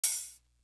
Shootem Up Open Hat.wav